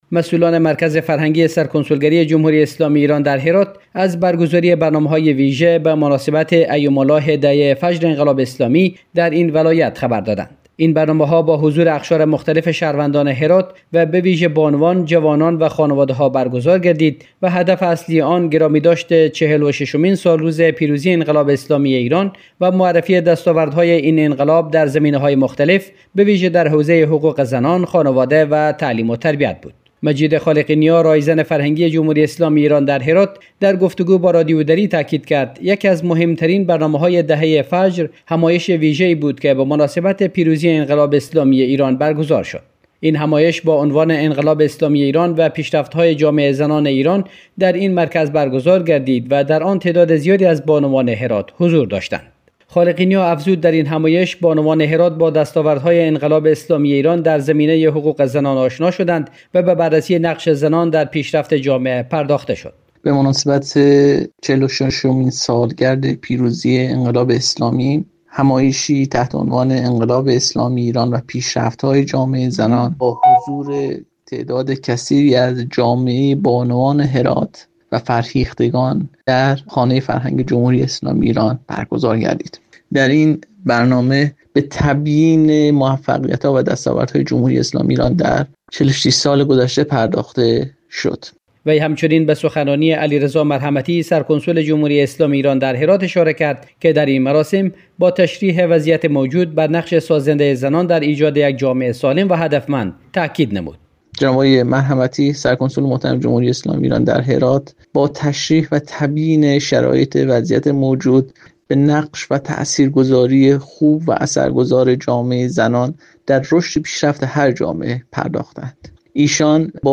در گفتگو با رادیو دری